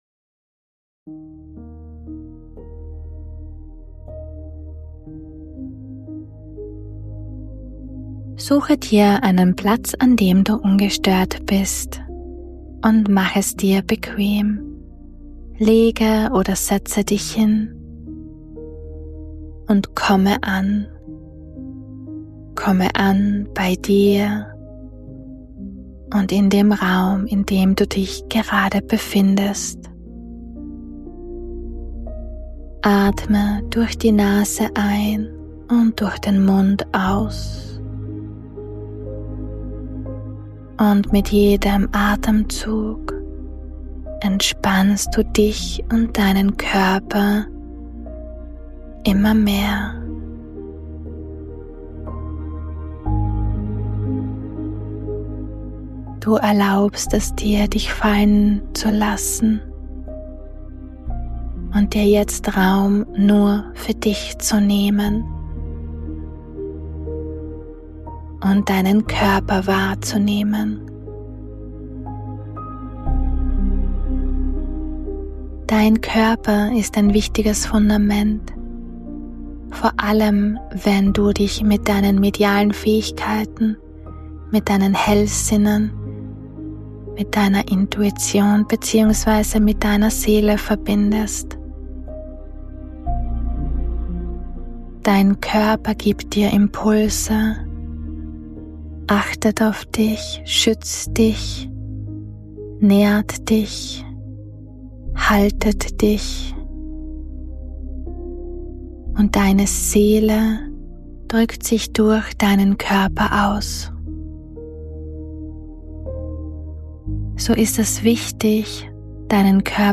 076 I Meditation: Nehme deinen Körper wahr ~ Intu Soul - Der Podcast